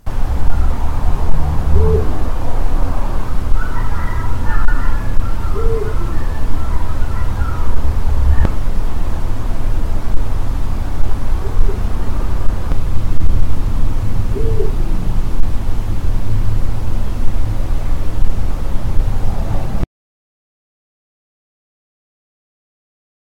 Oehoe
Bubo bubo
De grootste uil van Europa met zijn diepe, krachtige 'oe-hoe' roep.
Krachtige roep
De diepe 'oe-hoe' roep is kilometers ver te horen en één van de meest indrukwekkende geluiden in de Nederlandse natuur.
oehoe.mp3